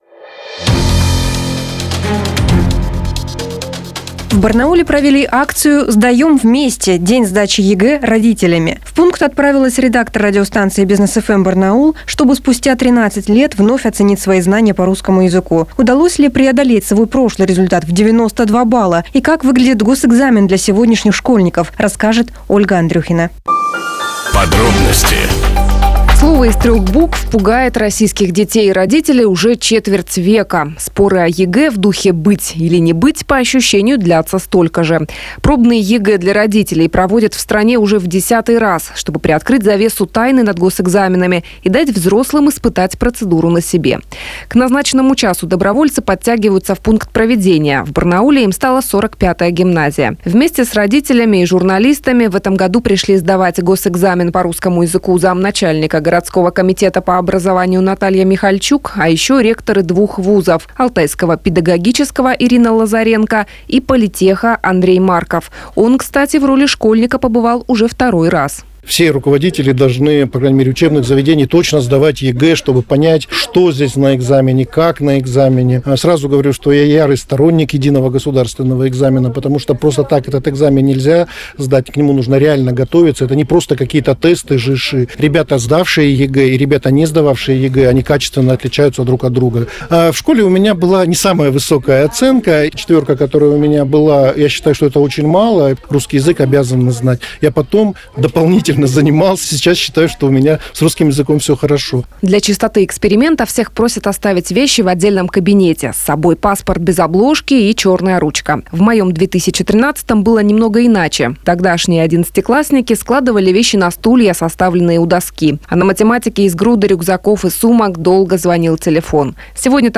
Сюжет на Business FM (Бизнес ФМ) Барнаул